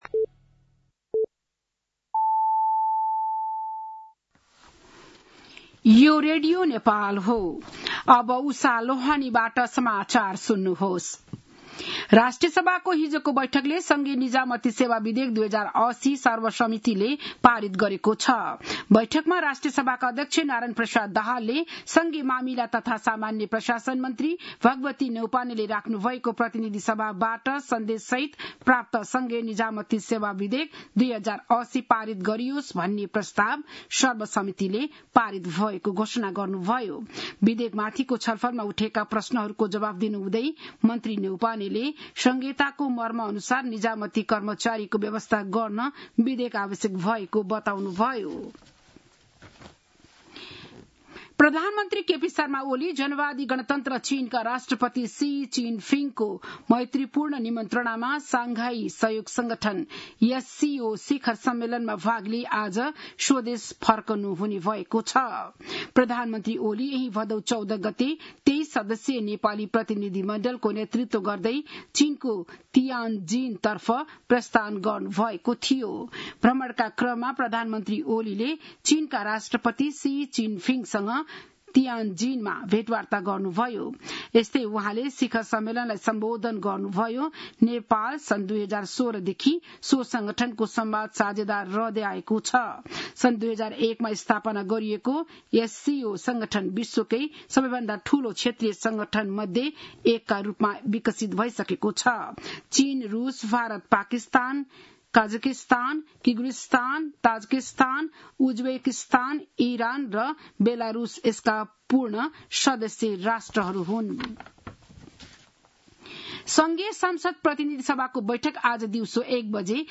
बिहान ११ बजेको नेपाली समाचार : १८ भदौ , २०८२